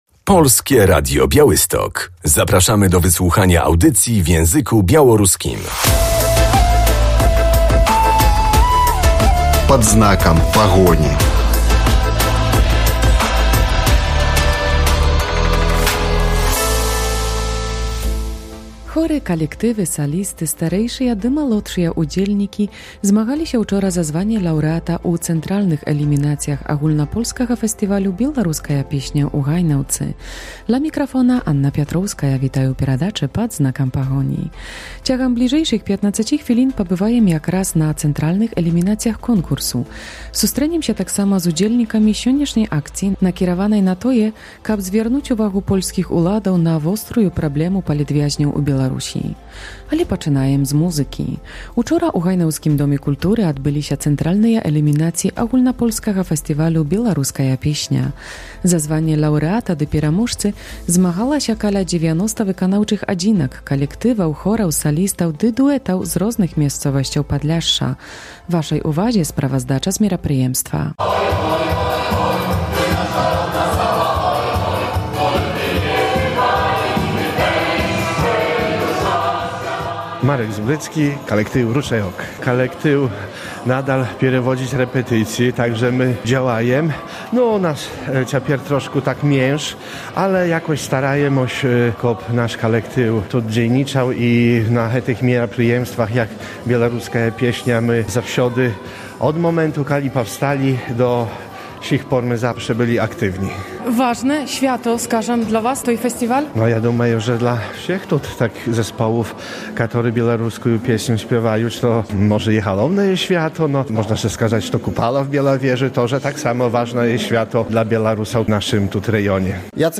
W audycji usłyszymy relację z centralnych eliminacji Festiwalu "Piosenka Białoruska 2024".